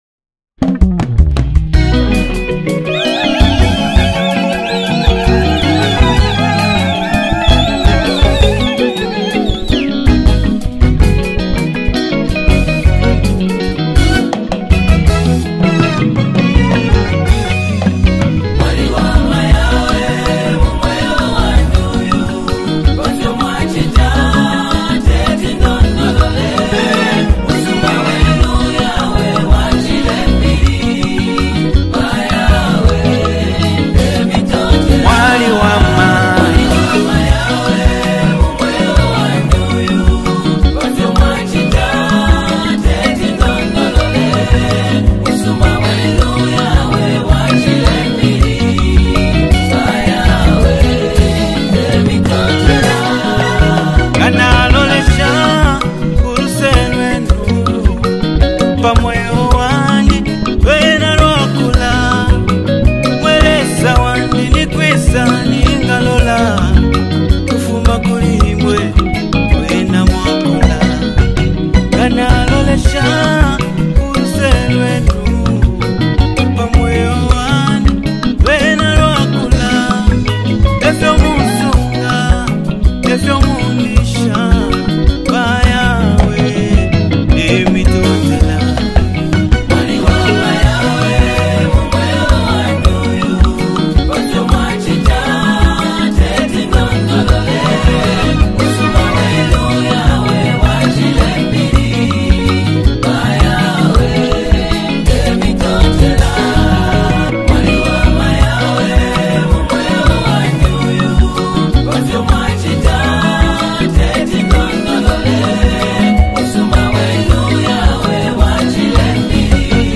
worship anthem